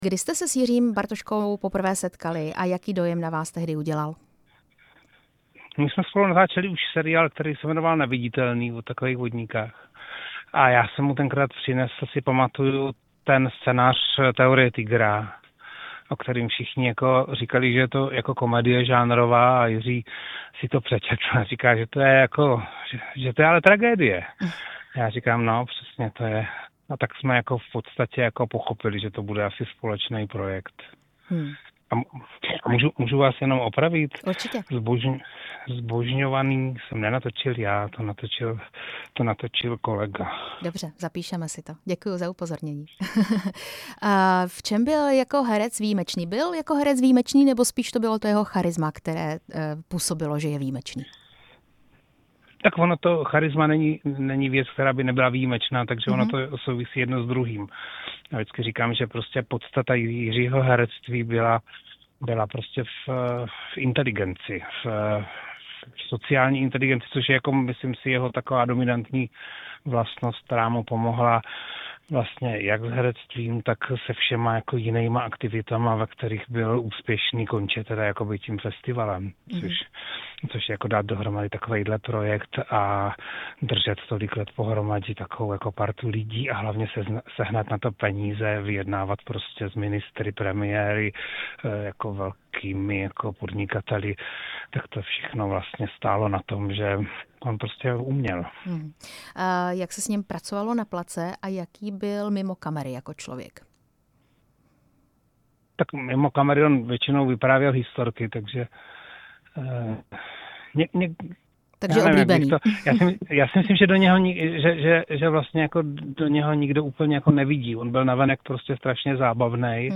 Rozhovor s režisérem a scénáristou Radkem Bajgarem